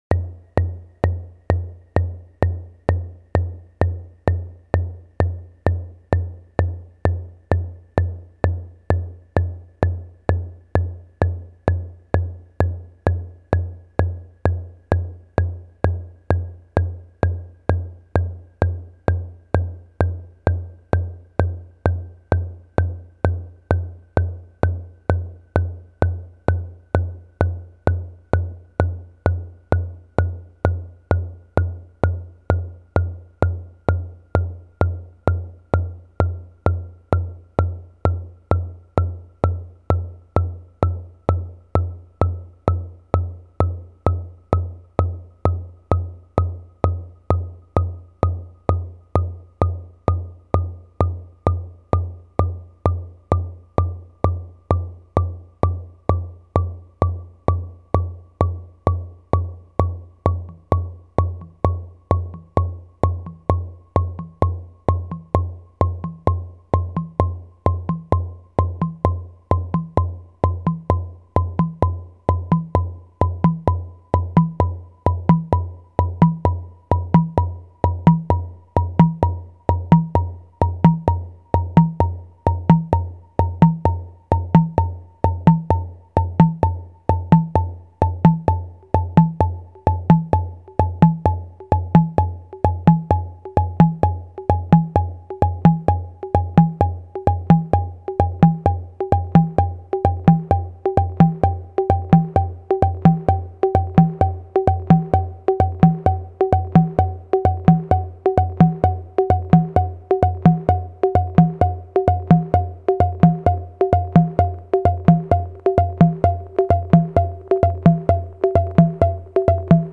Je crois reconnaitre une horloge … lol